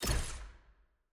sfx-jfe-ui-roomselect-enter.ogg